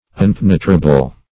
Unpenetrable \Un*pen"e*tra*ble\
unpenetrable.mp3